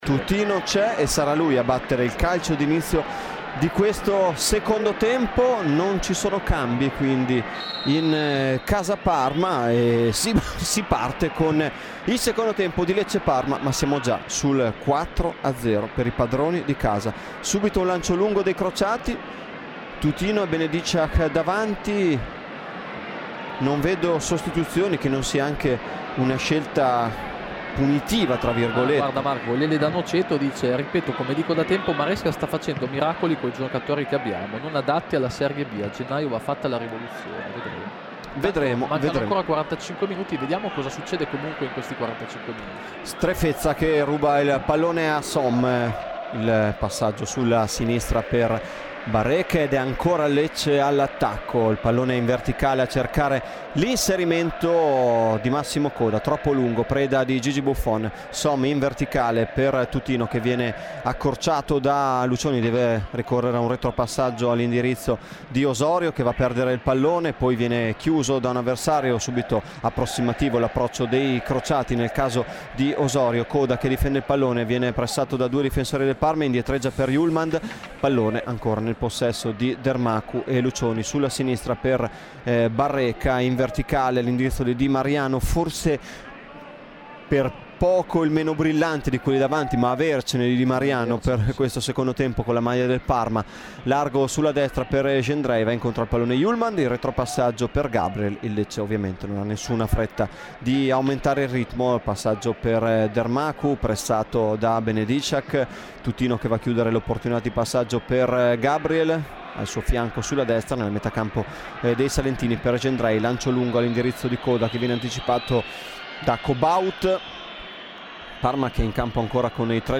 Radiocronaca
Commento tecnico